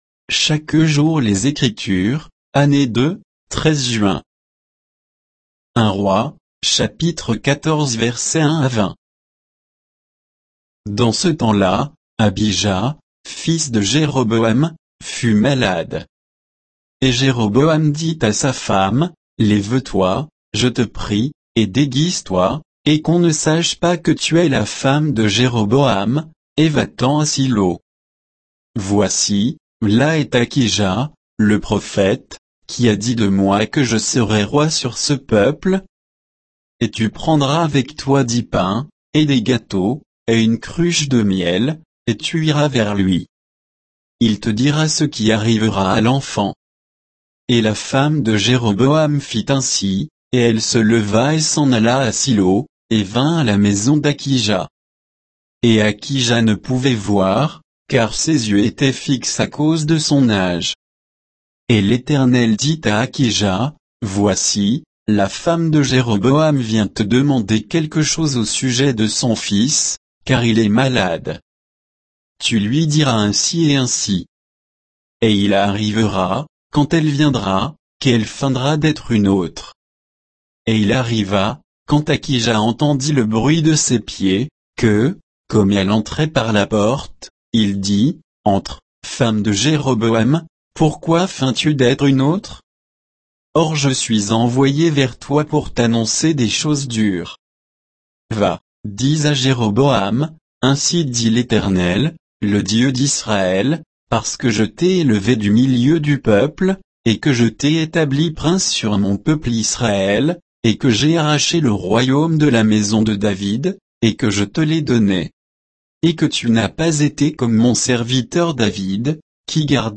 Méditation quoditienne de Chaque jour les Écritures sur 1 Rois 14